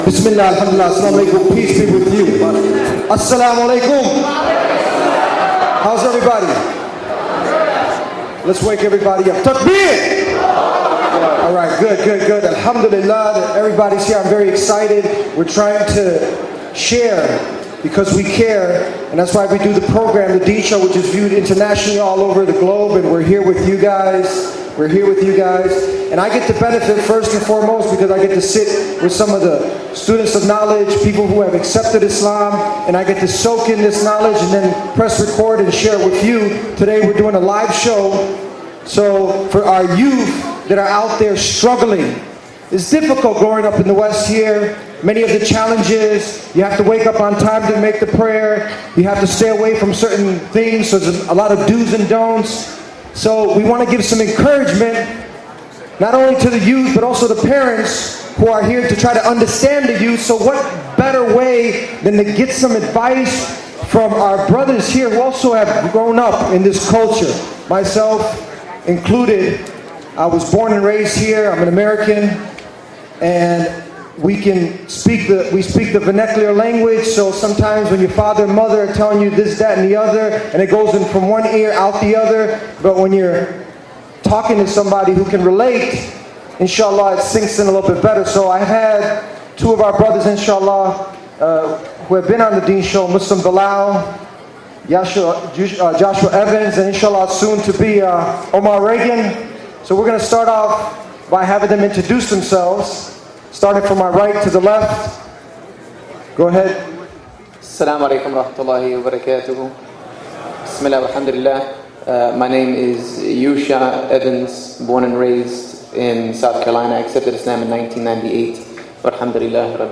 Struggling in the Deen (Live Show) – The Deen Show